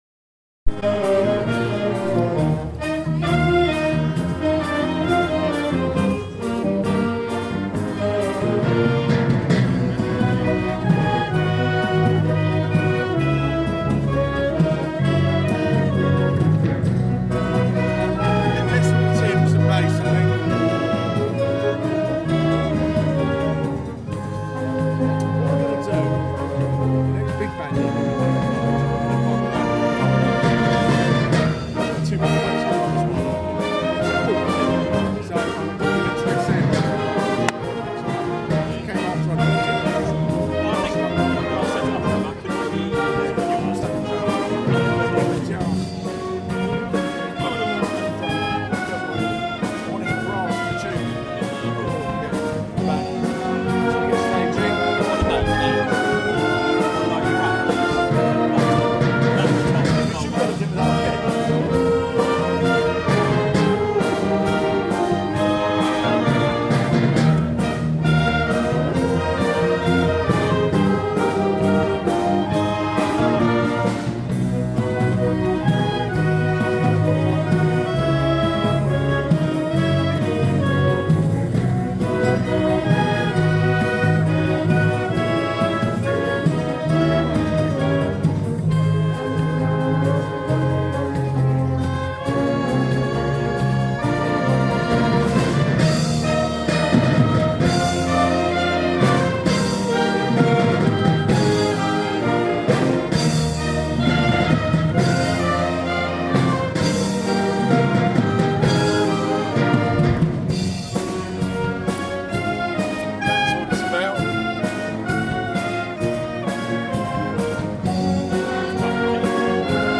Choro